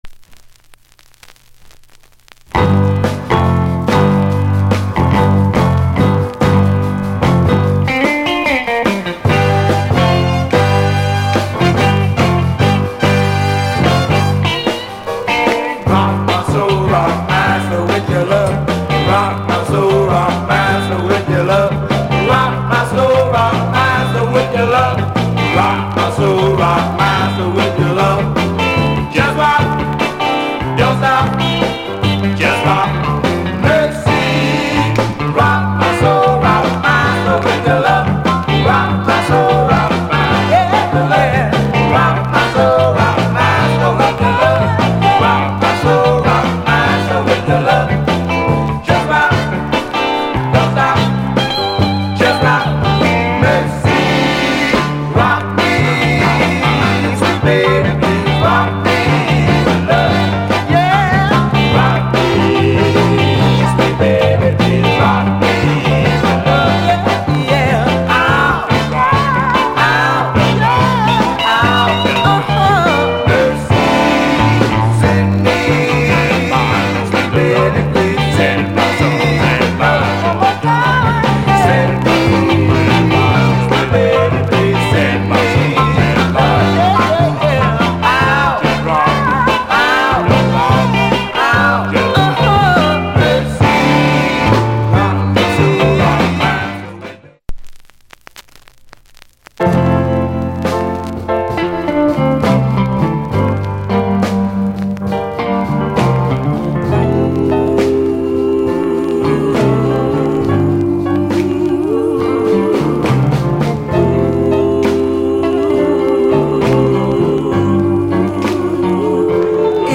Male Vocal Condition VG+